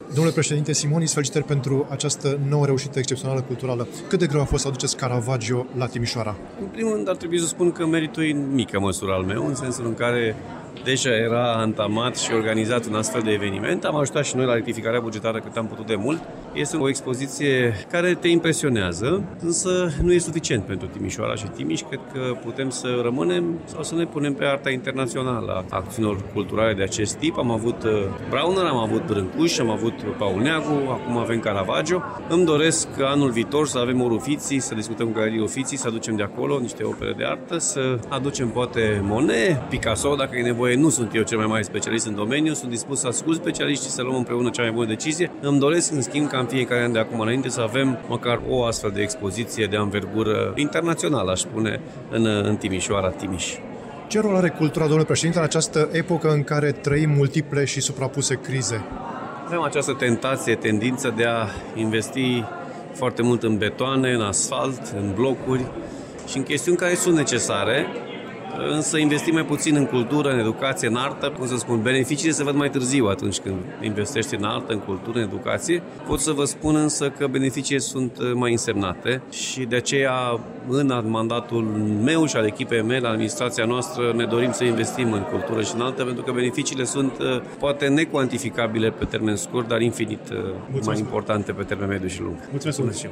Expoziţia este finanțată de Consiliul Județean Timiș. Am realizat un interviu cu Alfred Simonis, noul preşedinte al Consiliul Județean Timiş.